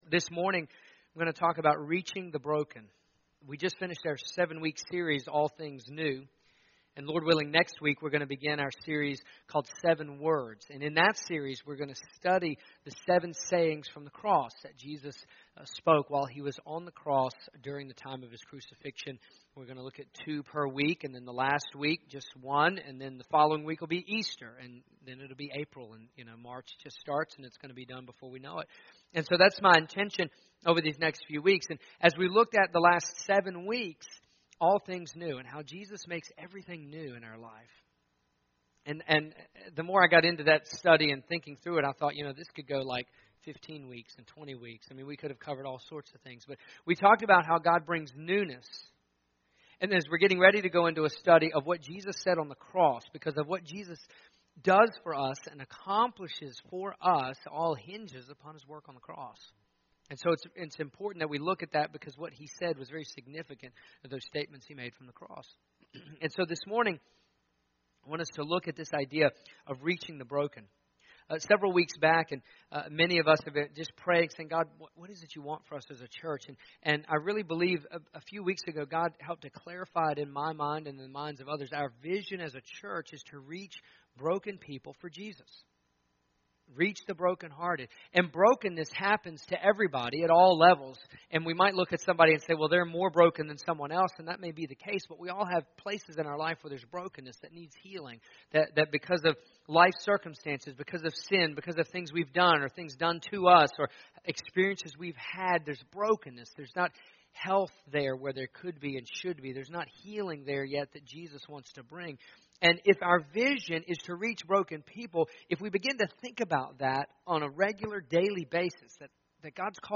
In spite of the ice yesterday, we had church. Here is the message I shared from Galatians 6 about reaching broken people for Jesus.